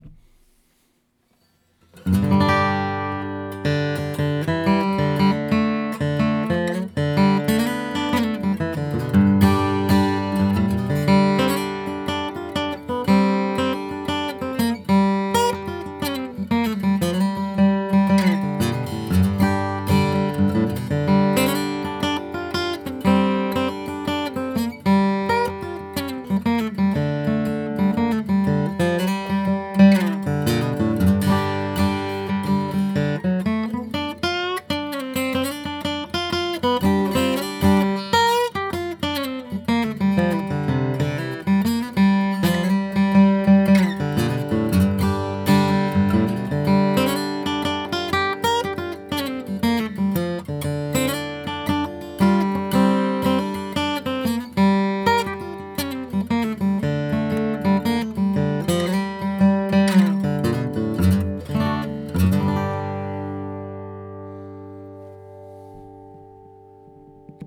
Martin D-18 sound samples